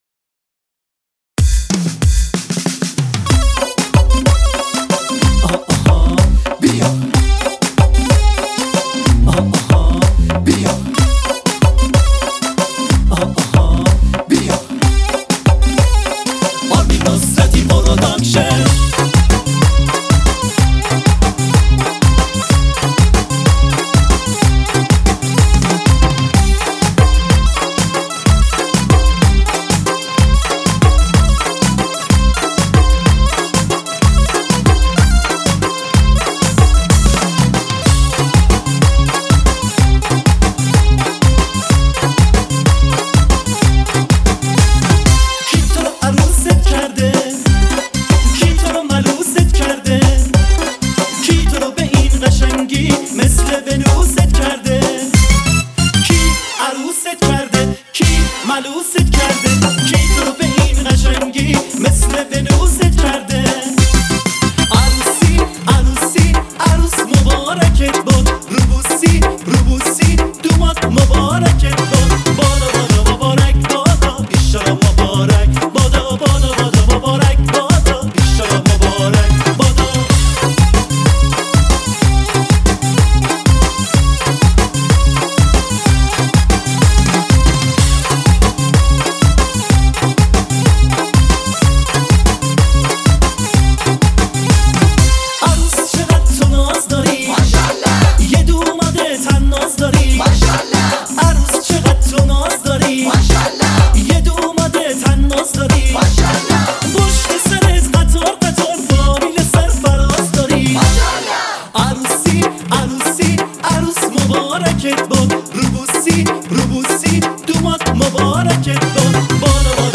آهنگ شاد عروسی: